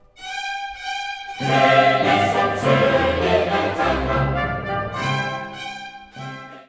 Osterhochamt am 23. April um 10:00 Uhr in St. Bonifatius, Großwelzheim.
Die Besetzung mit Pauken und Trompeten unterstreicht den festlichen Charakter der Musik.